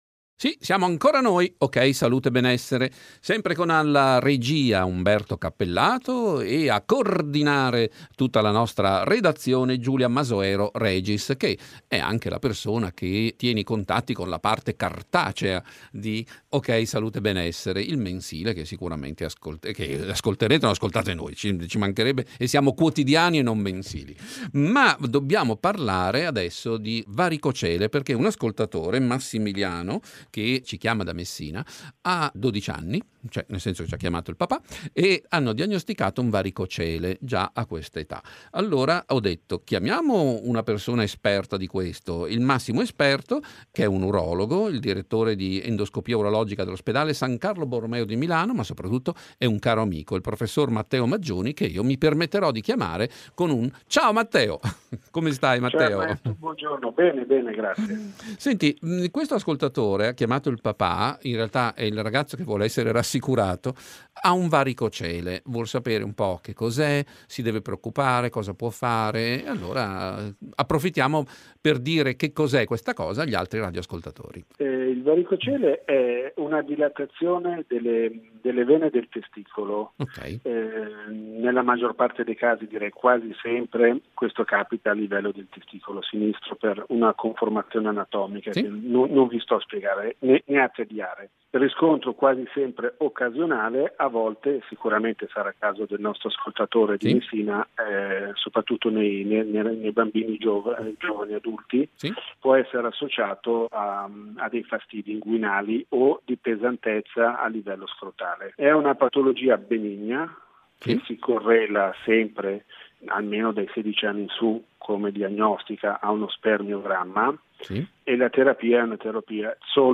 Intervista in esclusiva su LatteMiele